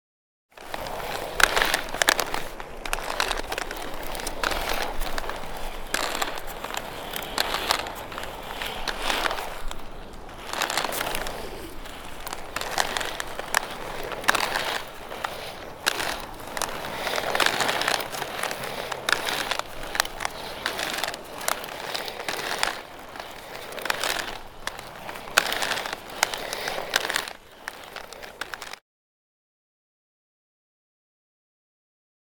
Звуки роликовых коньков
Звук катающихся на роликовых коньках